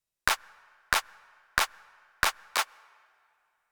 Here you will find rhythms with various types of notes in the bar.
Three quarter notes and two eighth notes.